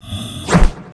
挥毒掌zth070516.wav
通用动作/01人物/03武术动作类/挥毒掌zth070516.wav
• 声道 單聲道 (1ch)